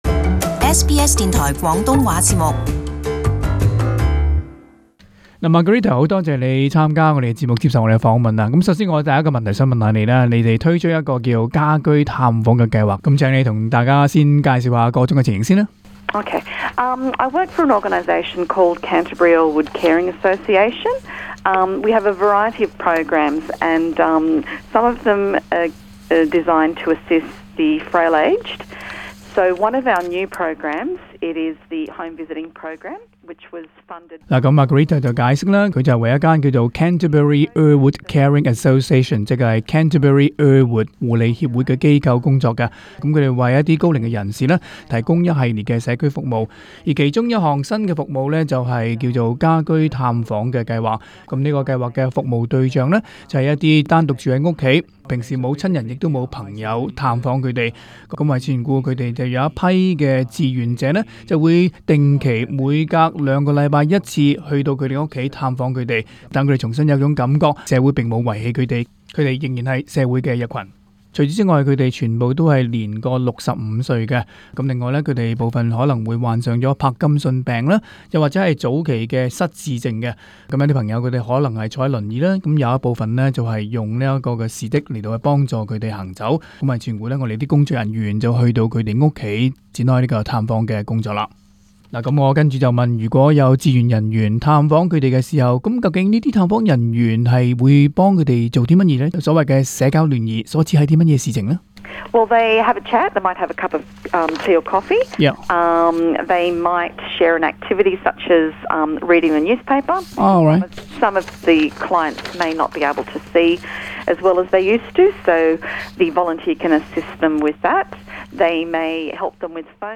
【社团专访】新州Canterbury 及 Earlwood 招募家居探访计划义工